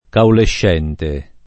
[ kaulešš $ nte ]